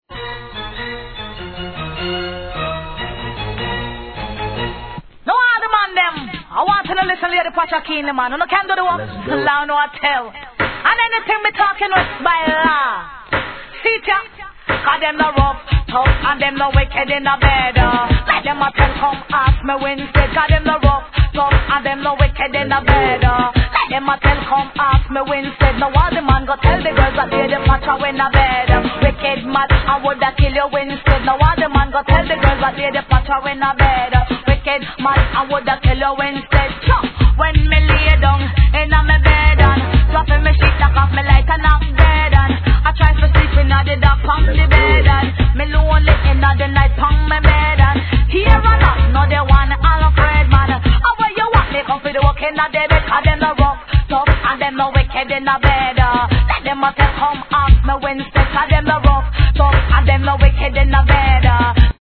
REGGAE
印象的なストリングスのイントロに、怒渋ベースでWICKEDフィメールDeeJay STYLE!!